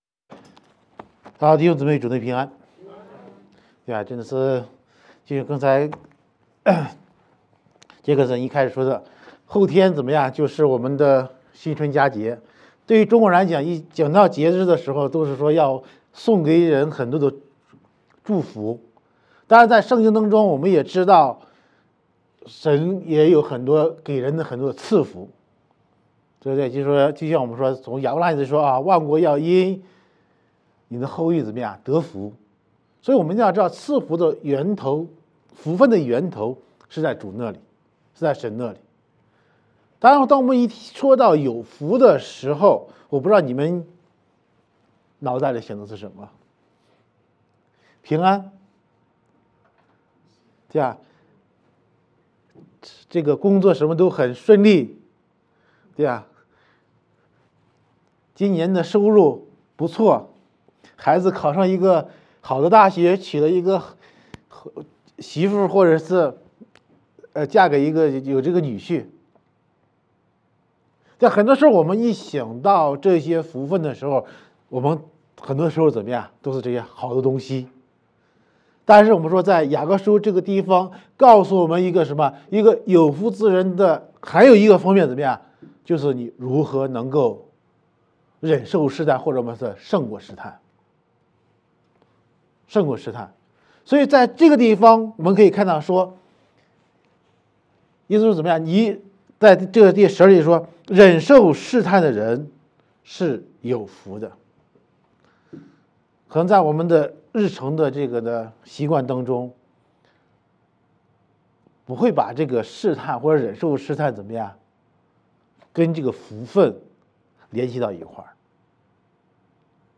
主頁 Home 關於我們 About Us 小組 Small Groups 事工 Ministry 活動 Events 主日信息 Sermons 奉獻 Give 資源 Resources 聯絡我們 Contact 有福之人——忍受試探